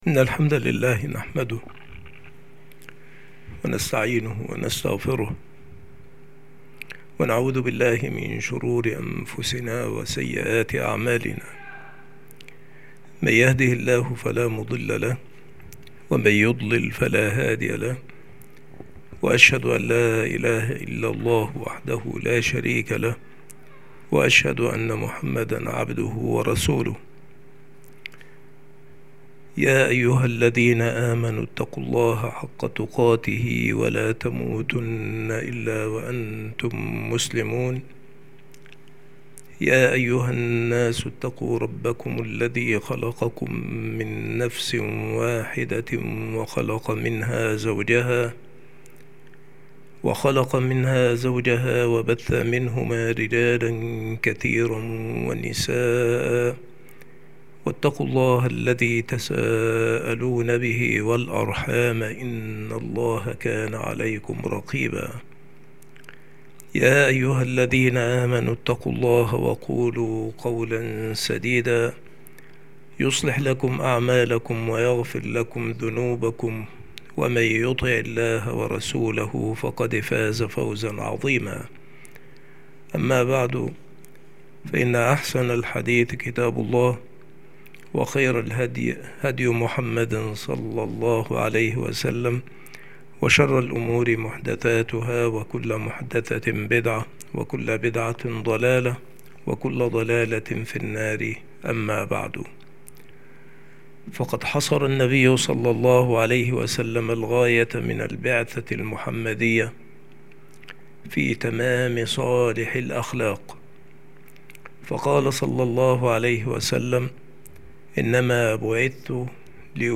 مكان إلقاء هذه المحاضرة المكتبة - سبك الأحد - أشمون - محافظة المنوفية - مصر عناصر المحاضرة : مقدمة في الكلام عن حسن الخُلُق.